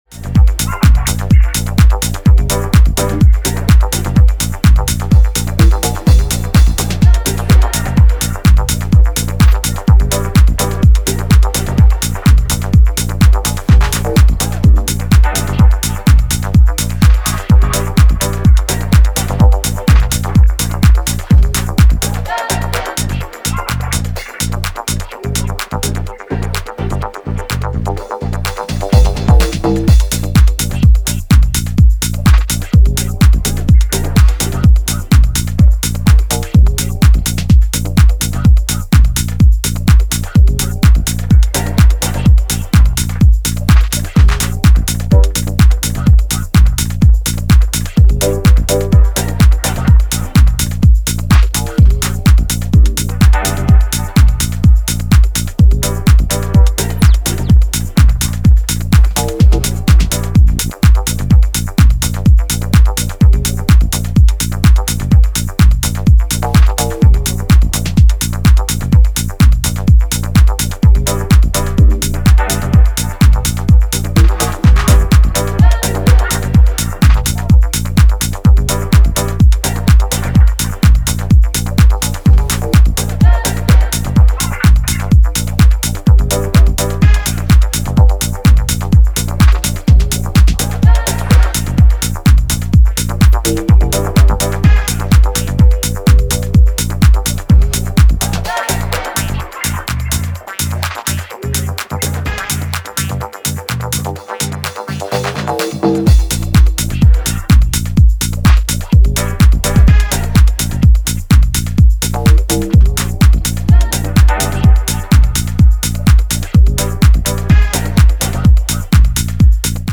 ヘヴィなミニマルチューン